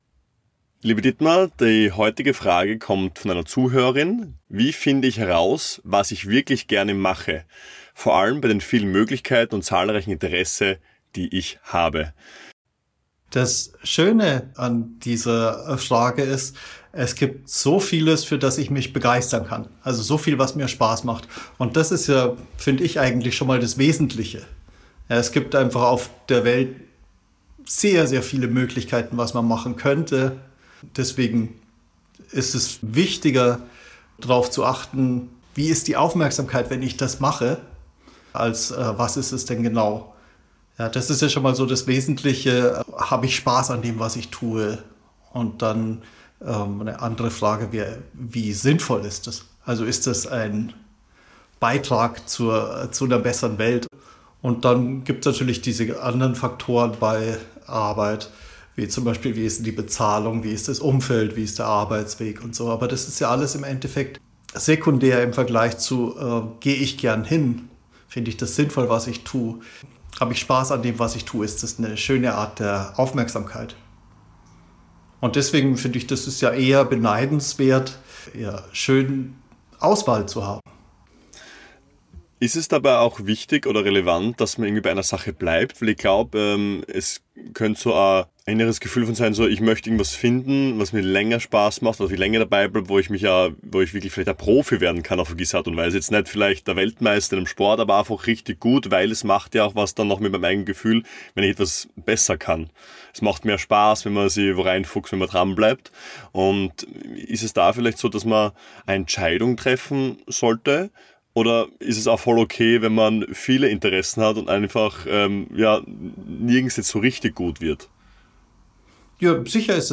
Im Gespräch wird deutlich: Es geht weniger darum, die eine richtige Sache zu finden.